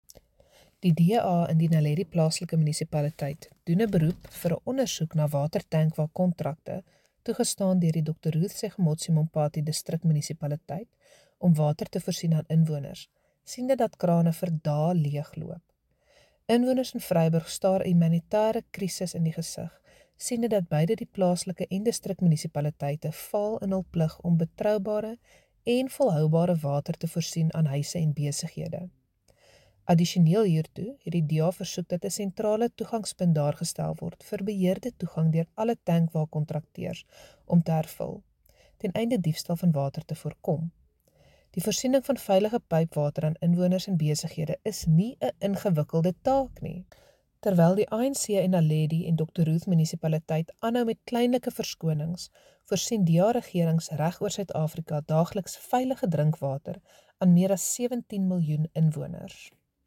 Note to Broadcasters: Find linked soundbites in
Afrikaans by Cllr Hendriëtte van Huyssteen